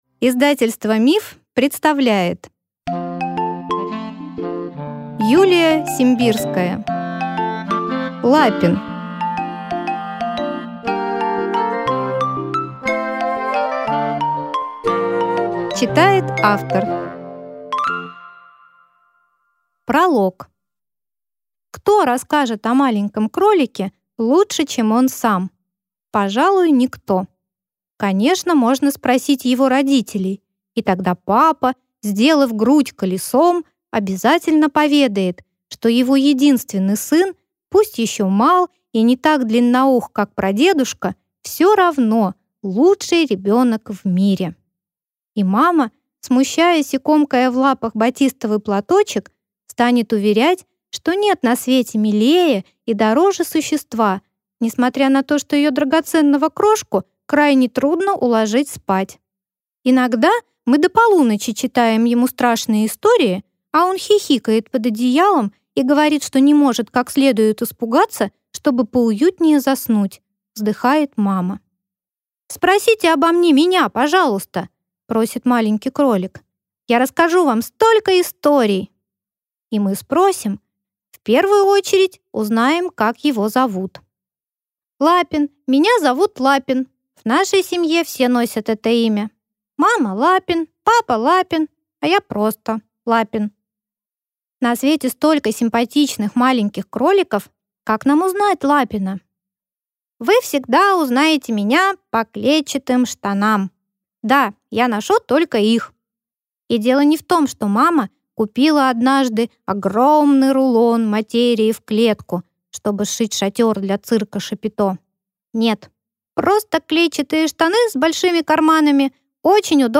Аудиокнига Лапин | Библиотека аудиокниг
Прослушать и бесплатно скачать фрагмент аудиокниги